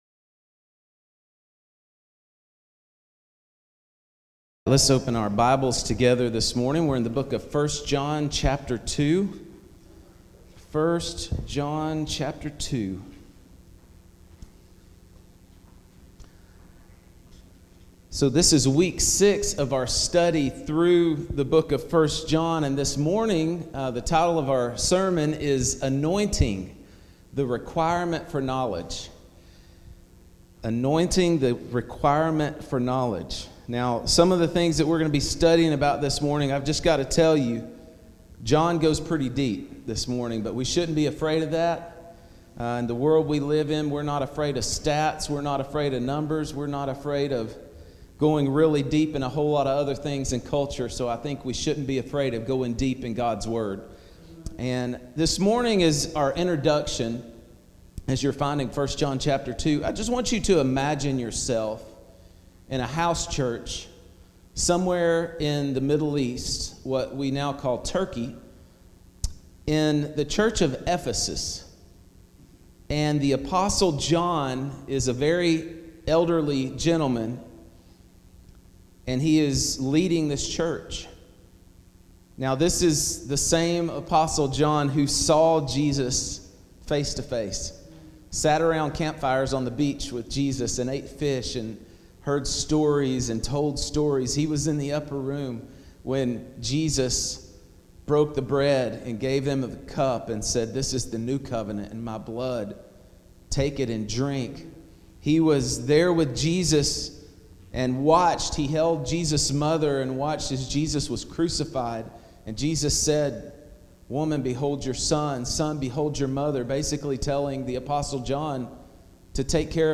Week-6-Sermon.mp3